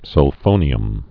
(sŭl-fōnē-əm)